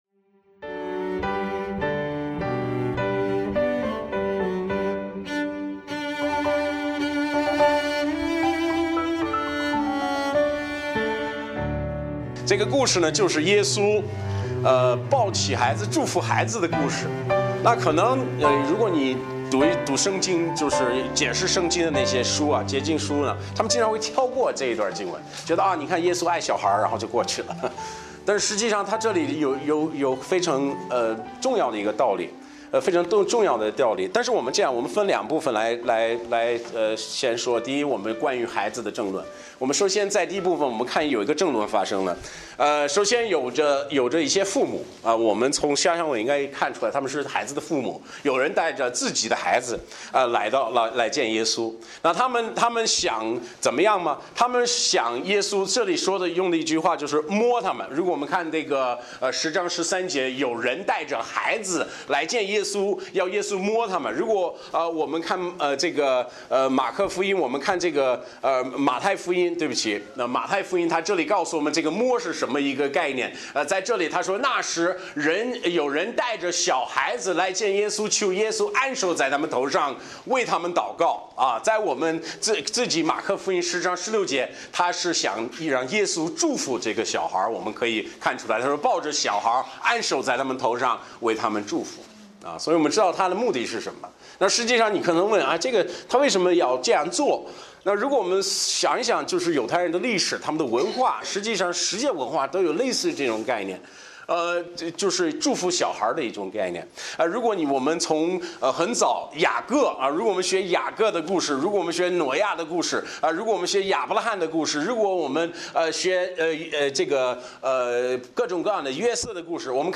Bible Text: 玛可福音 1：13-15 | 讲道者